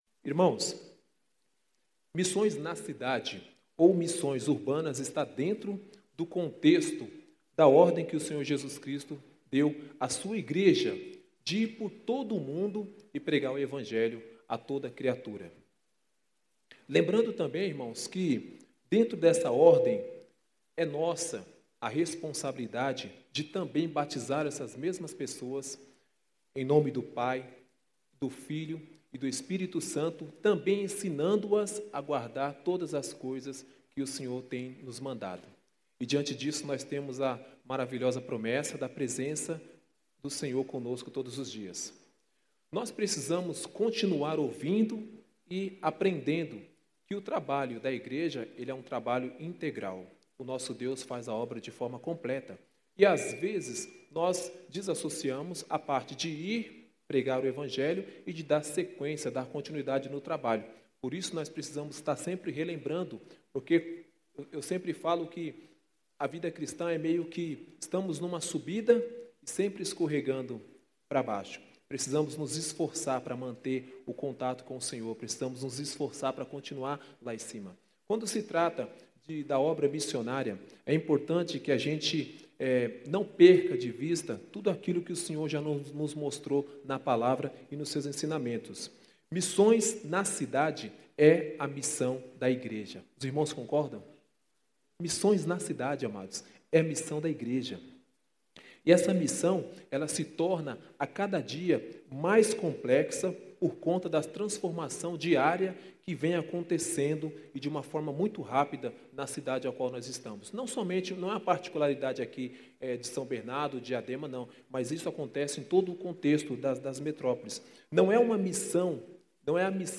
Culto da Conferência Missionária 2019